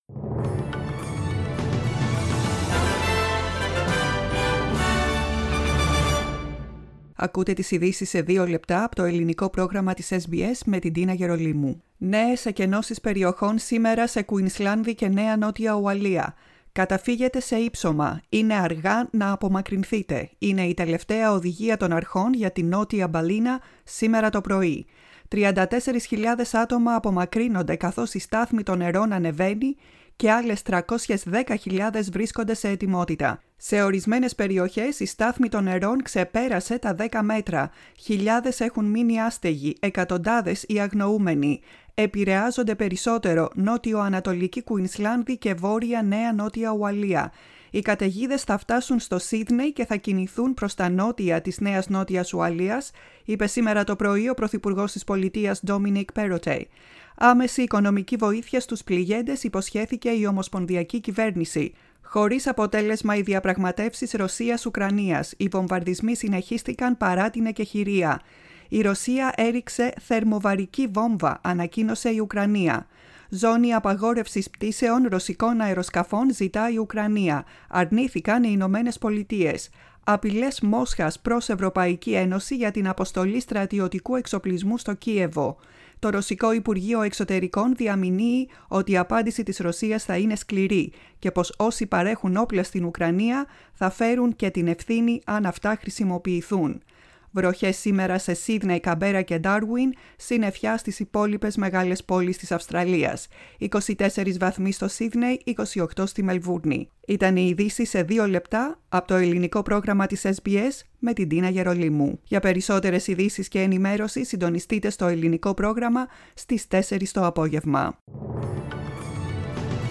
News Flash in Greek, 01.03.2022